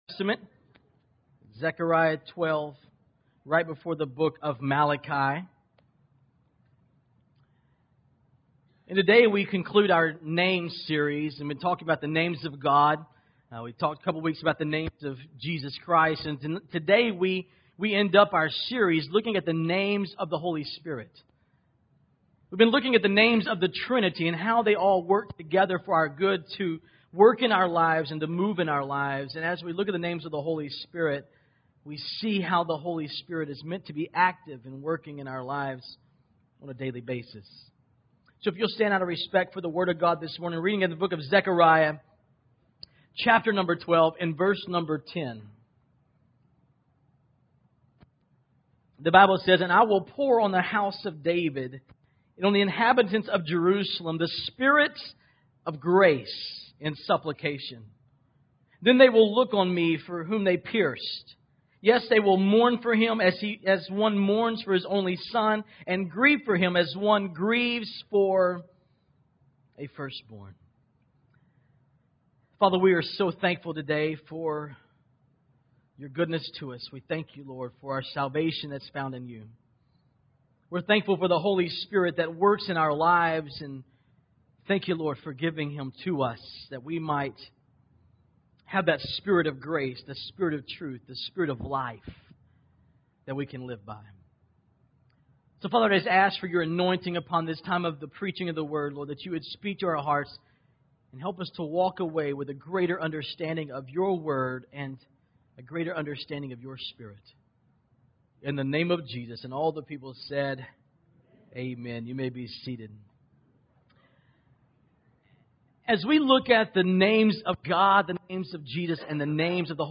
Here is yesterday's message: